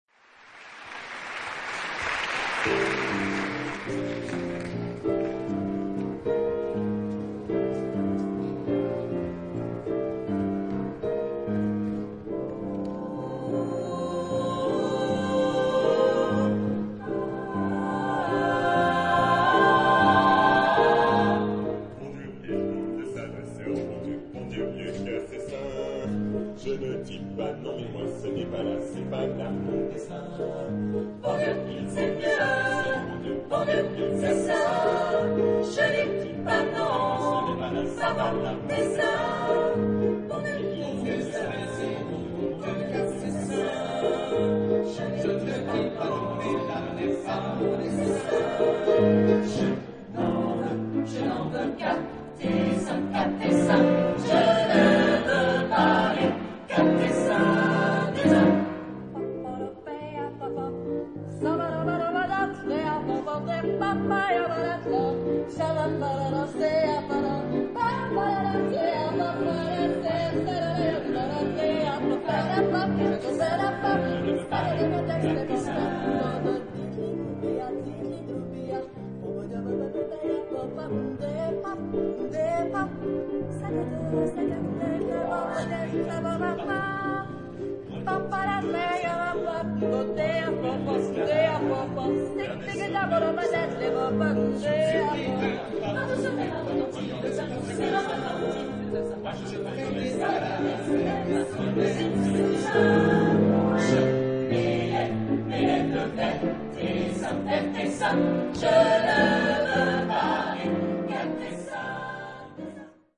Genre-Style-Forme : Jazz choral
Type de choeur : SMATBarB  (6 voix mixtes )
Solistes : Baryton (1) OU Soprano (1)
Instruments : Piano (1)